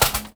R - Foley 109.wav